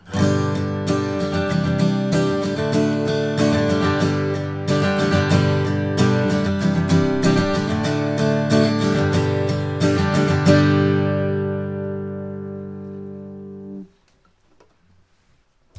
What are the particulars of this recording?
He made under fixed conditions samples of each guitar.